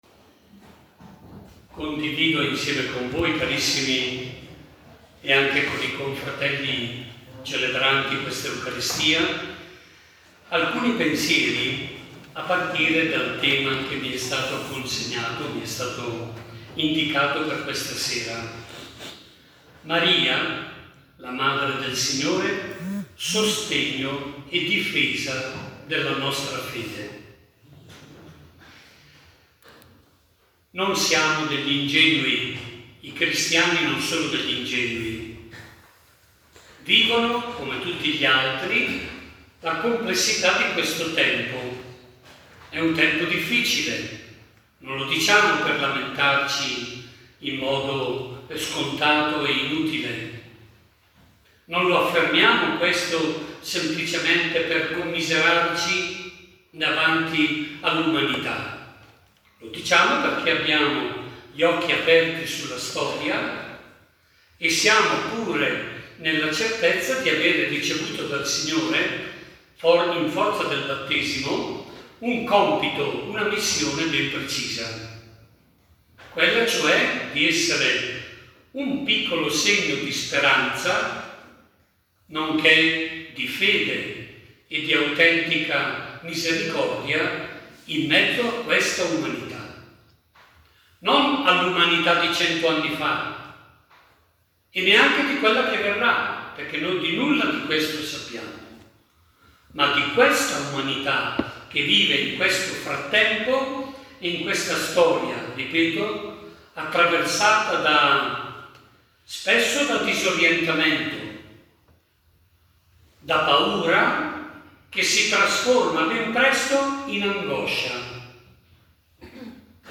Celebrazione del 11 agosto 2025